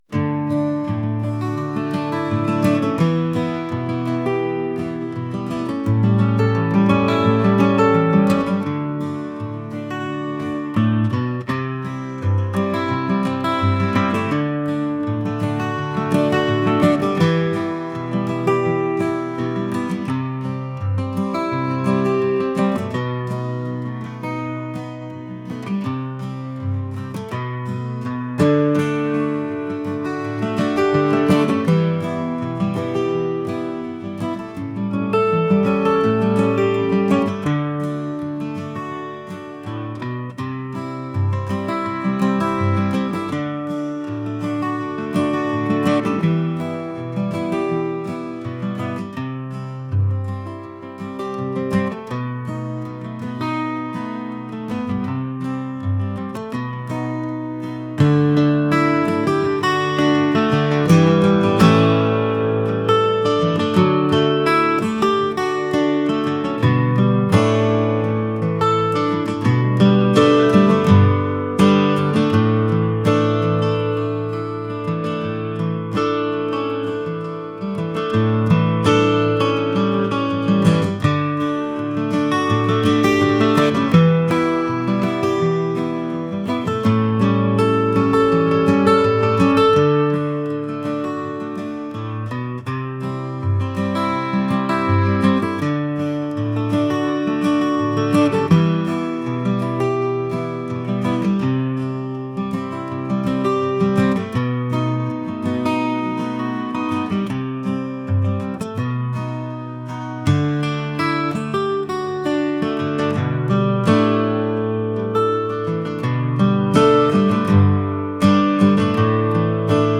acoustic | folk